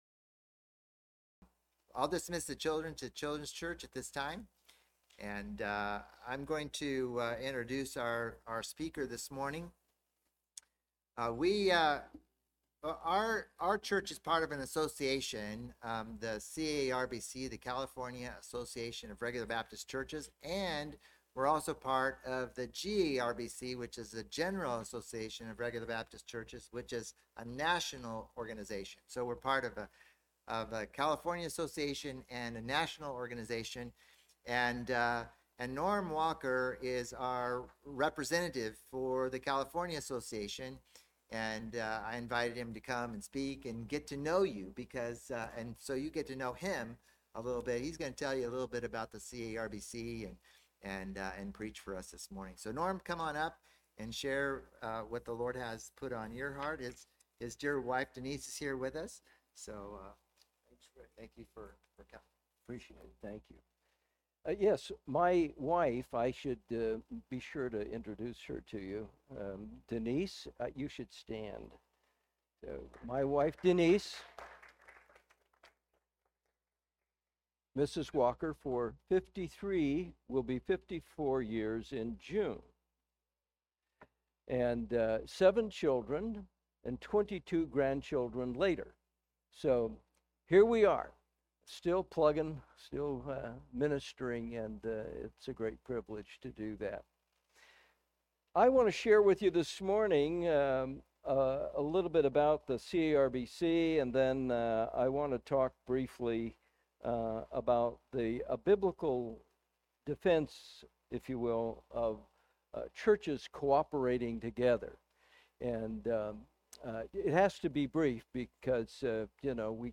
Special Sermon